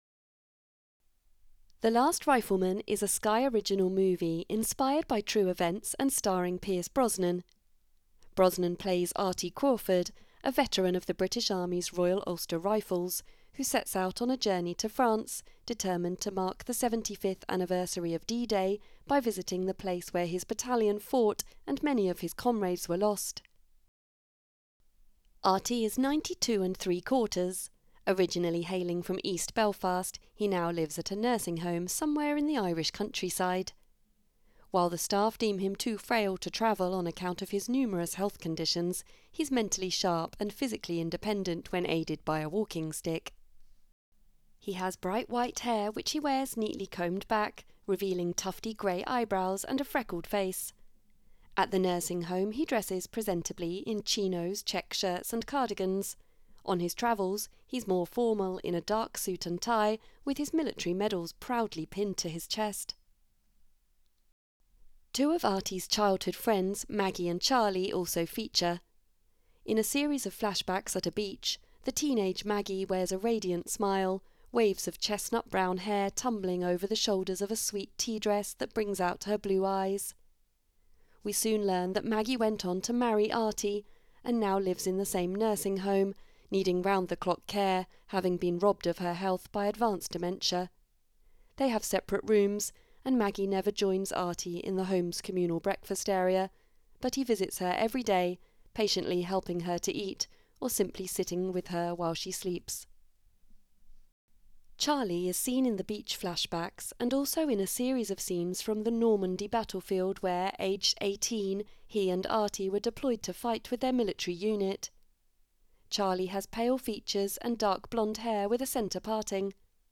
The Last Rifleman - AD Introduction
AD_introduction_-_The_Last_Rifleman.wav